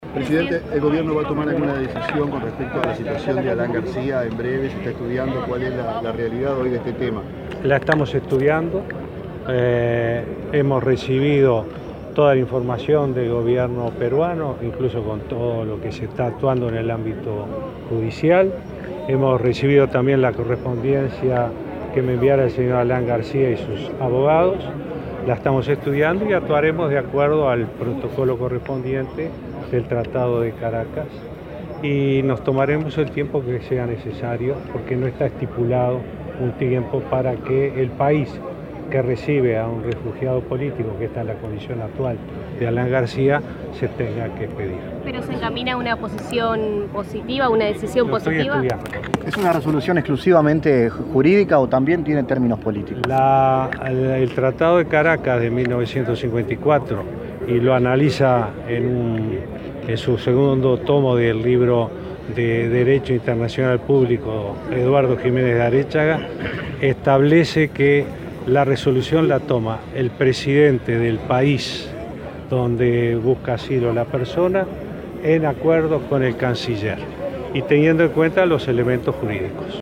El presidente Tabaré Vázquez confirmó a la prensa en Artigas que el Gobierno está estudiando el pedido de asilo político del ex presidente peruano Alan García, de acuerdo al protocolo del Tratado de Caracas.